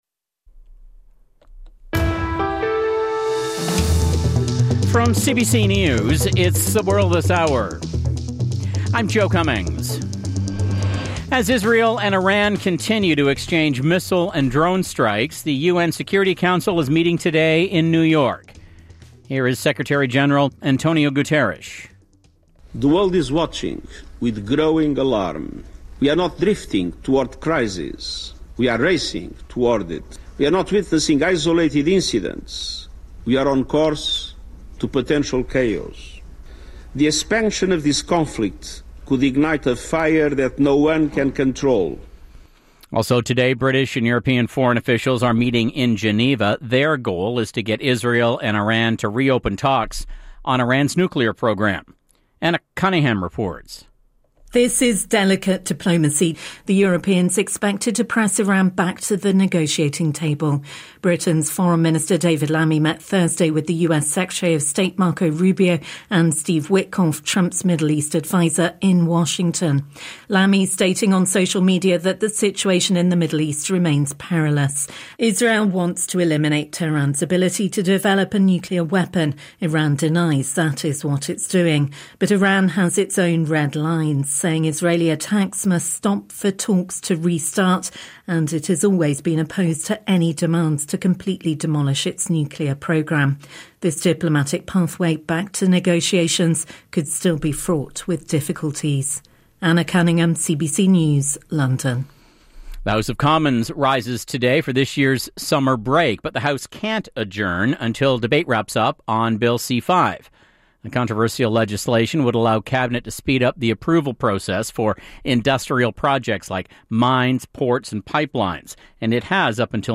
hourlynews.mp3